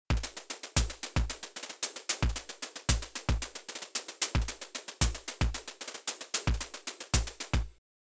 Well, it does sound pretty good this way but I do lose the attack a bit (was a sharp pitch env) and I still can’t really go to much towards noise because it just sounds like a decay of a clap if I do. Also the noise part has not got enough high frequency content really.
Semi-open hat works for me as well :stuck_out_tongue: It’s a bit more like hitting the hat with the side of the stick.